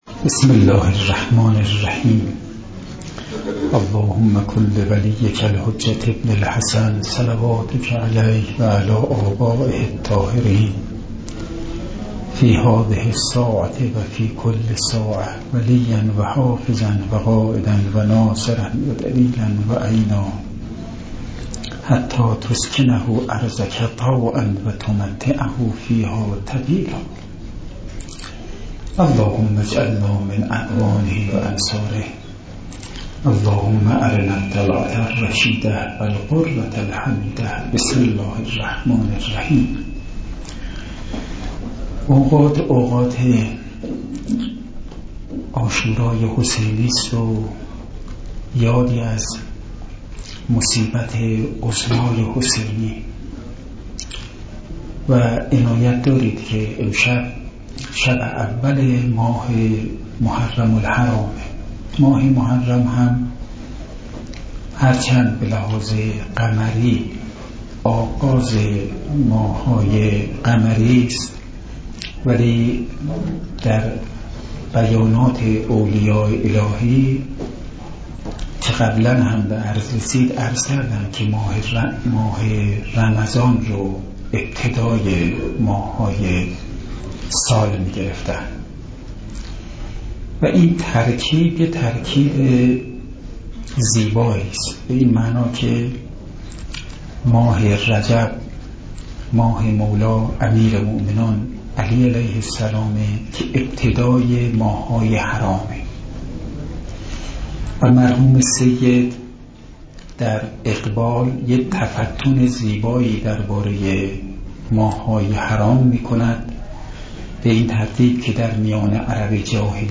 سخنرانی
سخنرانی شب اول محرم